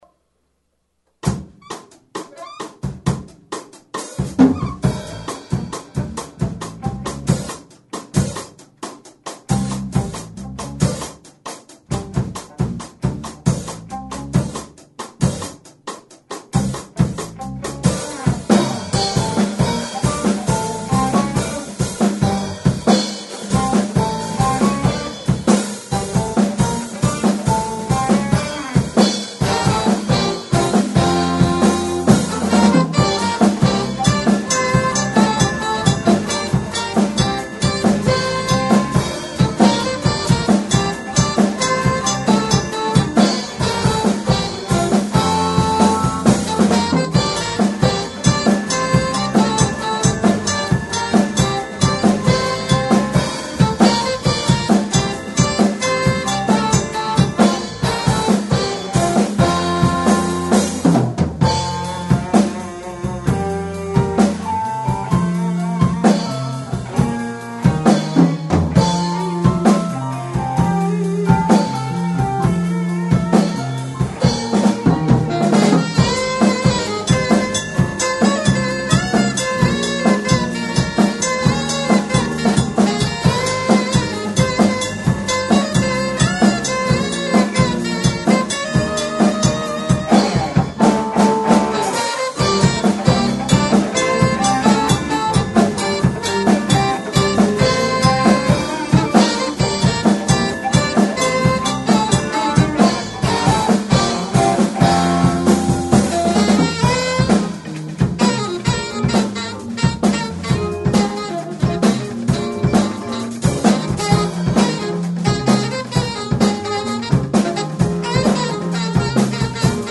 게시판 연주음악 A little dolly bird - WAVE 합주 녹음
건반소리가 작게 녹음되어 썰렁한 느낌이 없지 않지만 실제 공연때는 대박이었다는..^^ 첫 합주 녹음이라 완성도는 좀 떨어집니다..